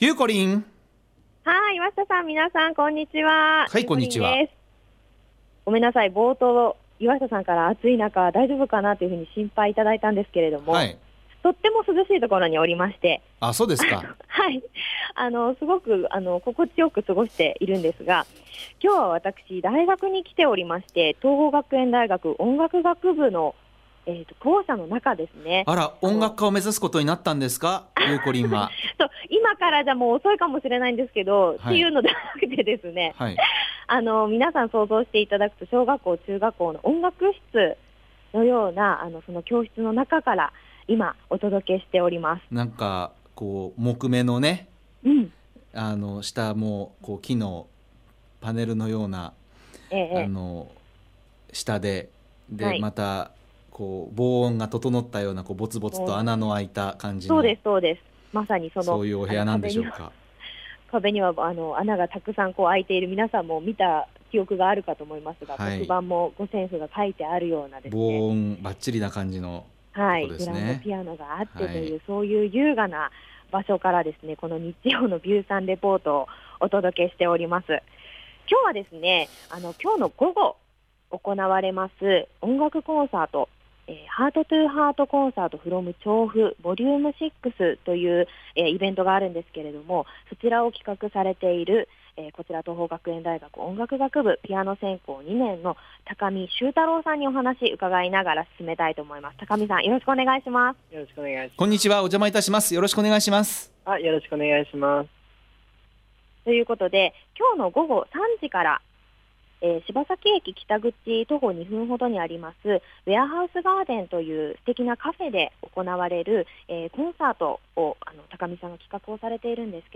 ★びゅーサン 街角レポート
レポートは音楽室からのお届けでした。